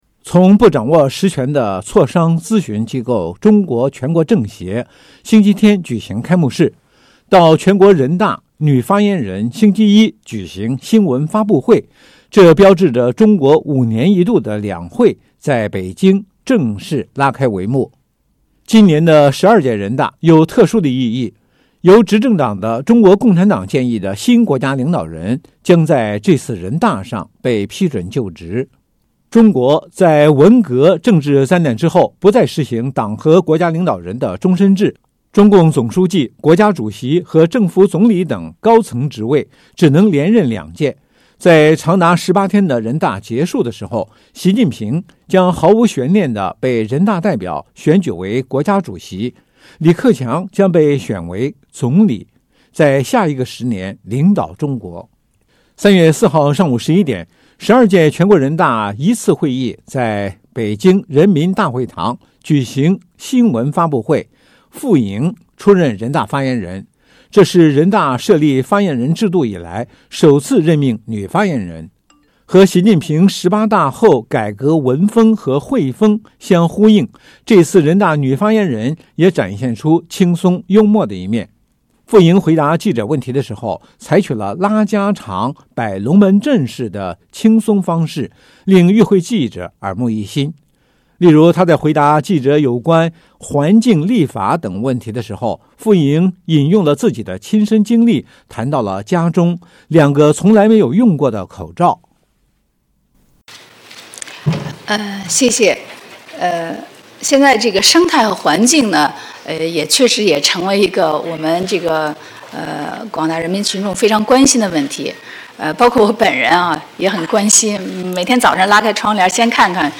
3月4日上午11时，十二届全国人大一次会议在北京人民大会堂举行新闻发布会。
傅莹回答记者问题的时候，采取了拉家常式摆龙门阵的轻松方式，令与会记者耳目一新。